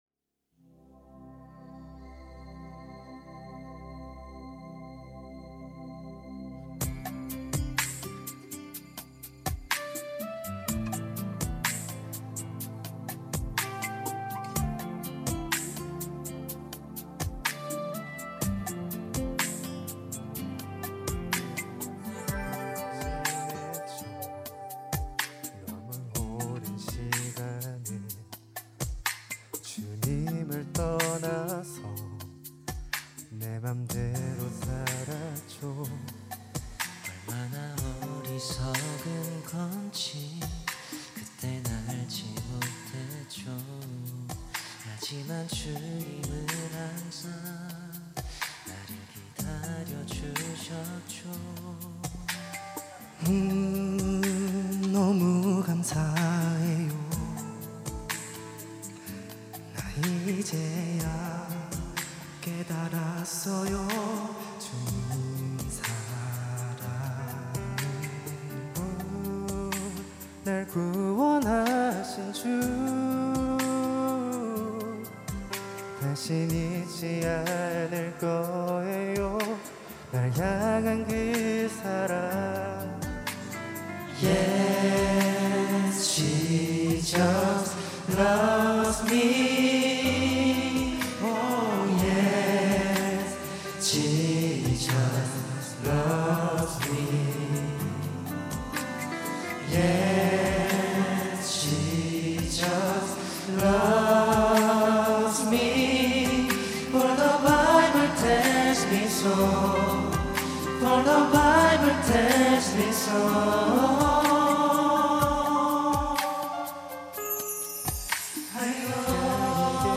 특송과 특주 - Jesus Loves Me